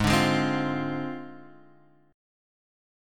G#6b5 chord